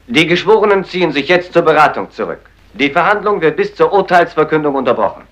Beisitzer
12g-beisitzer.mp3